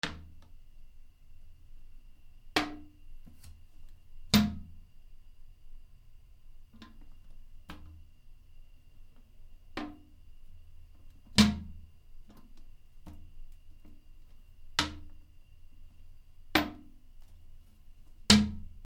トイレ 便座の上げ下ろし
/ M｜他分類 / L05 ｜家具・収納・設備 / トイレ
『カン』